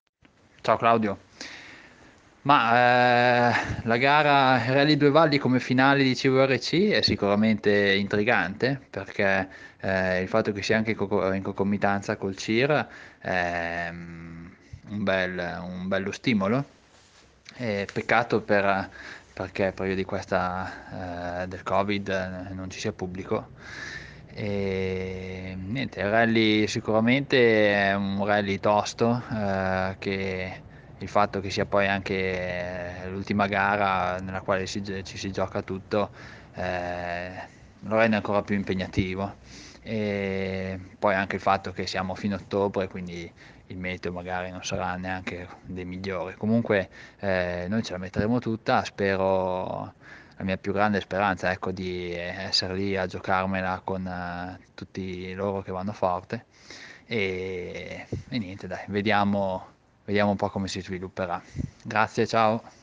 Interviste al Rally Due Valli
Interviste pre-gara